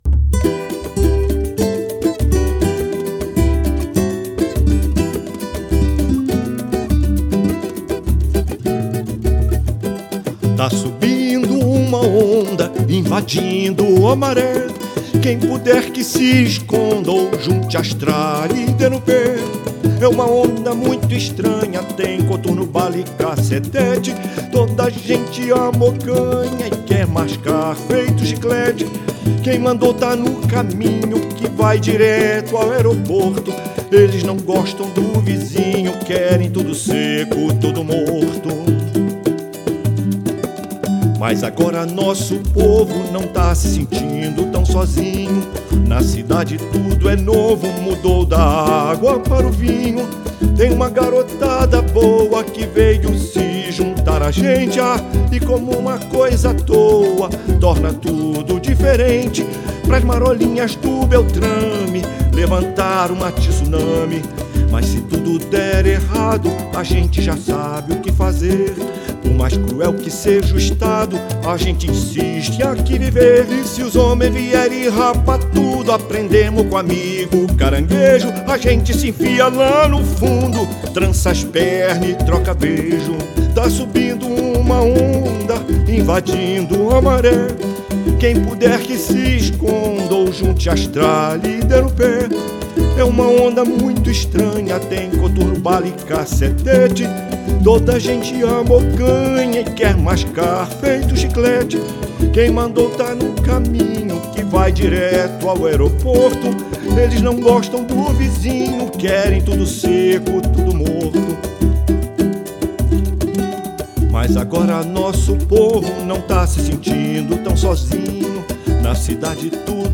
NOTA: a canção abaixo foi inspirada pela invasão da Maré que levou à morte dez de seus moradores, muitos sem nenhuma passagem pela polícia.